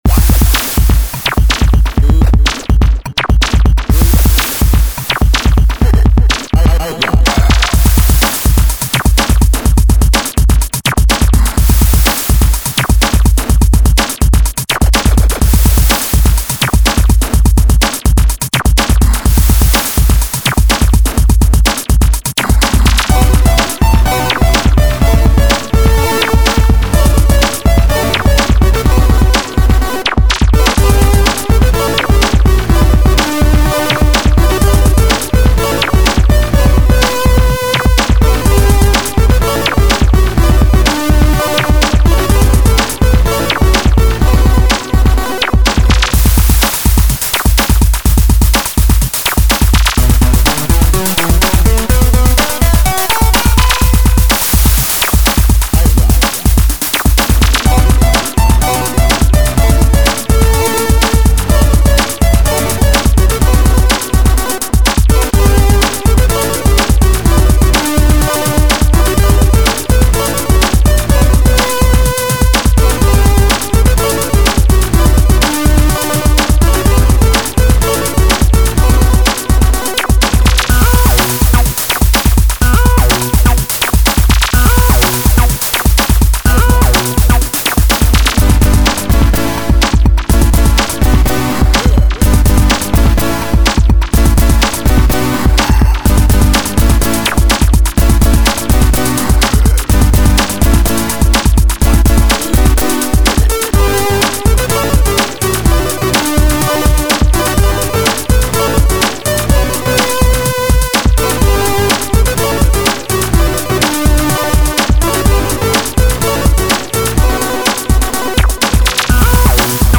Electro LowFi Remix
Really synthy